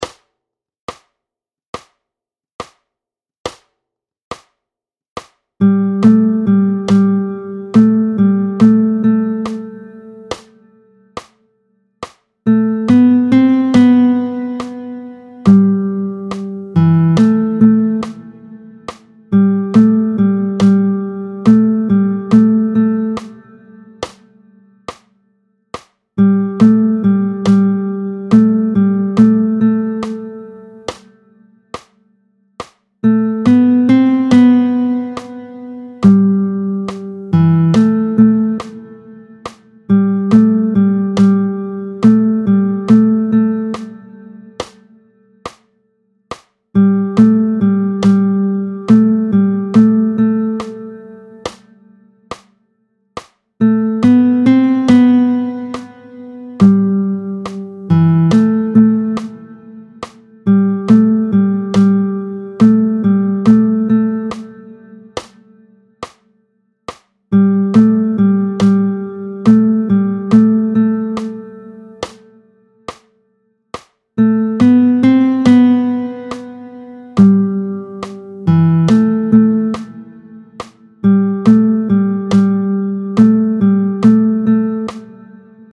Mélodie
tempo 70